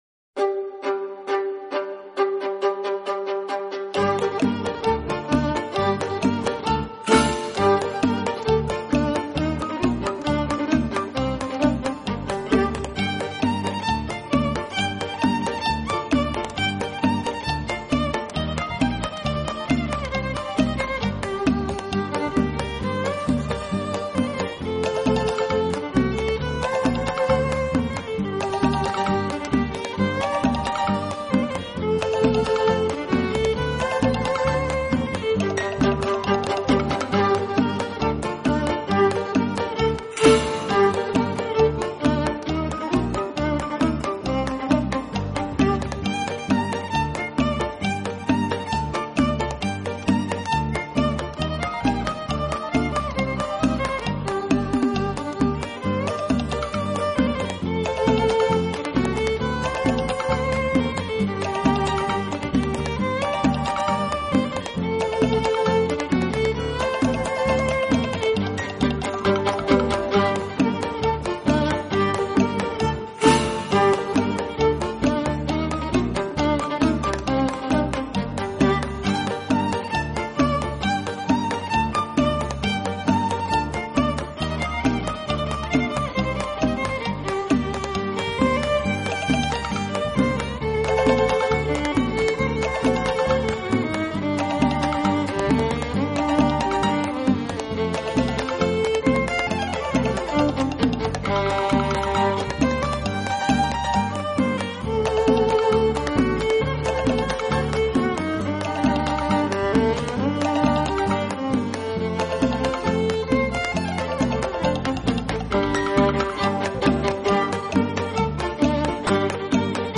主奏为小提琴，由钢琴、吉他、 贝斯、竖琴、斑鸠琴、大提琴及一些民俗传统乐器加入编排，使得乐曲有更鲜明的 活泼度。
畅快的舞动音符宛如飘泊 原野之风，阵阵催动。